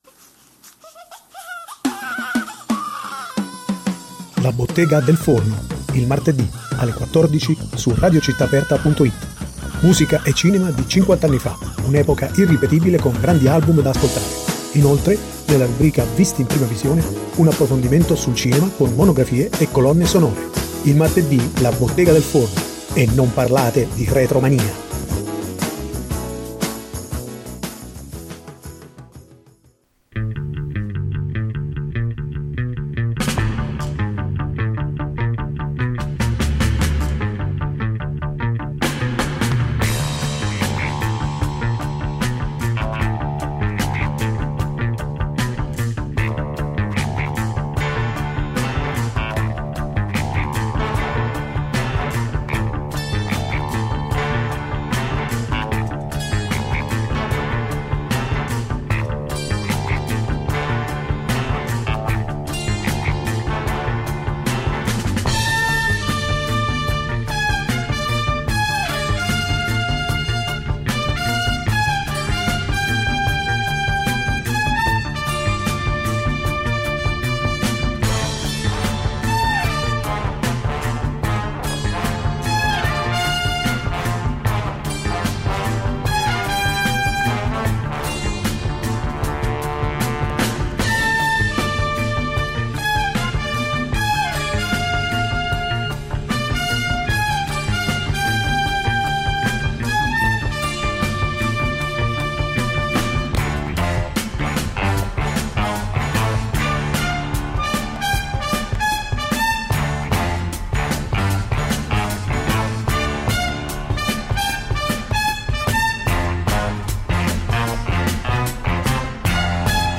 Ospite d’onore della rubrica, il Maestro Franco Micalizzi, autore dell’efficace colonna sonora, tra i più importanti autori di musiche da film, protagonista di una frizzante quanto sincera intervista ai microfoni di RCA.